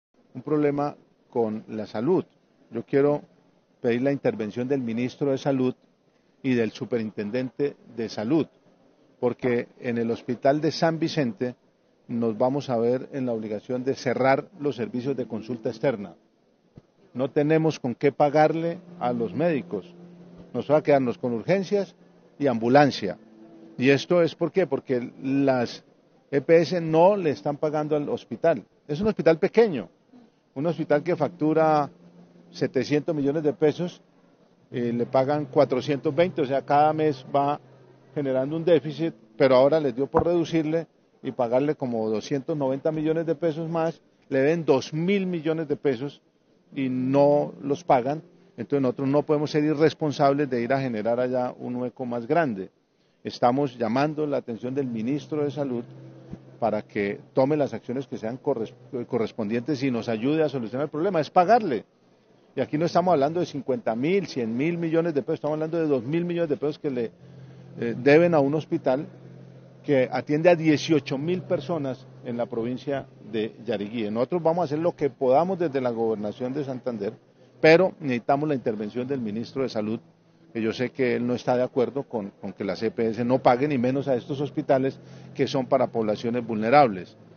Juvenal Díaz Mateus, Gobernador de Santander